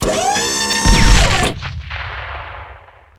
cannon.wav